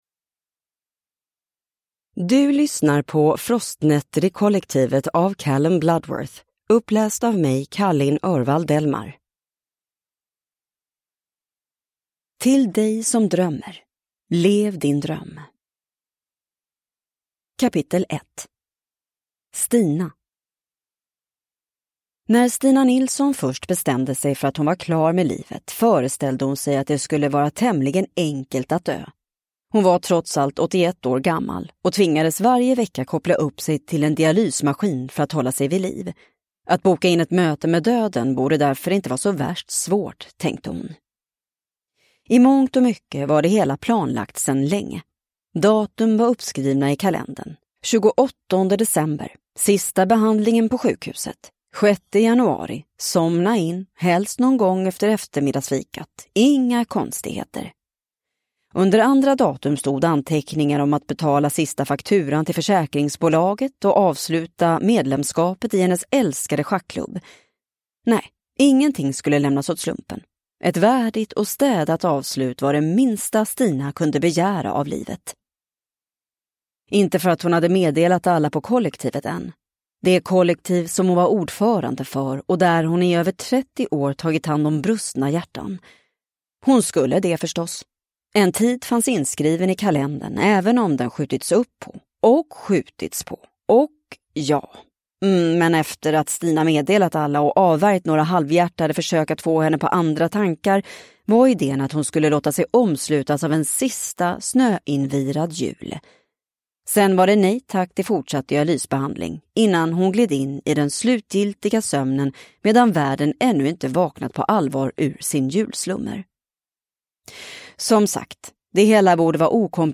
Frostnätter i Kollektivet (ljudbok) av Callum Bloodworth